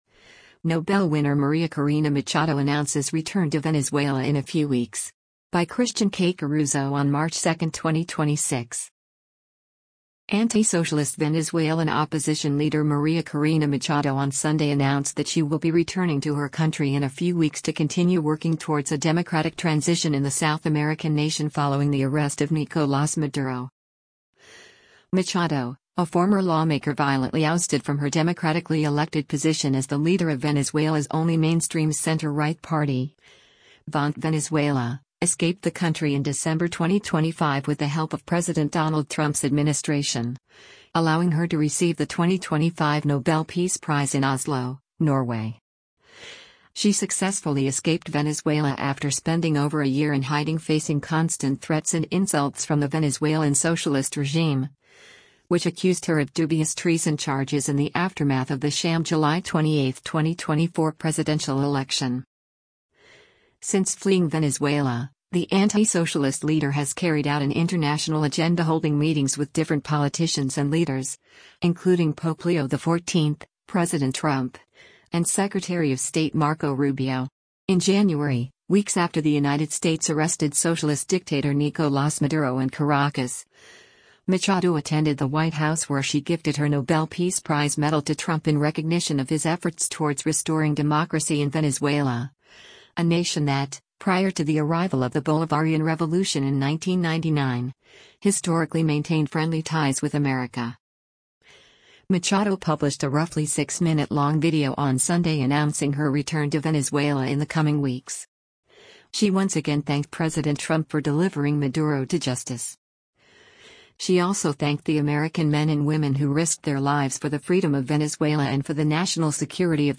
Machado published a roughly six-minute-long video on Sunday announcing her return to Venezuela in “the coming weeks.” She once again thanked President Trump for delivering Maduro to justice.